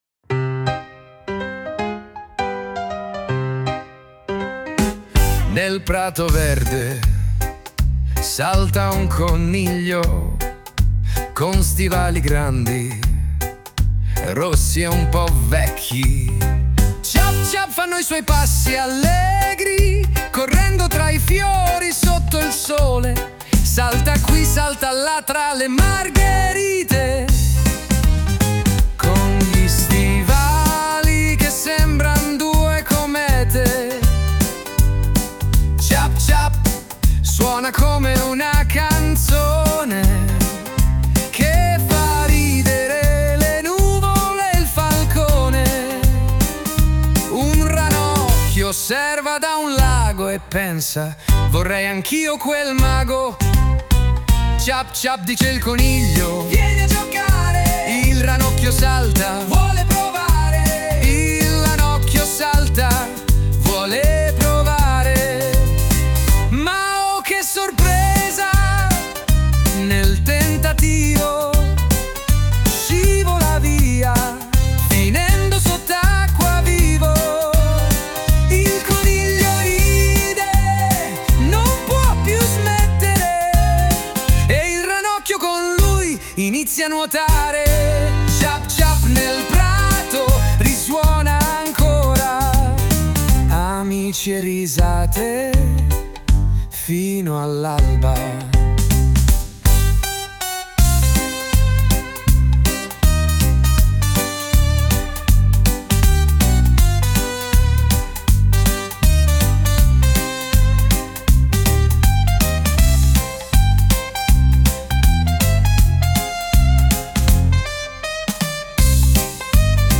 🎧 Ascolta🎶 Filastrocche📺 GUARDA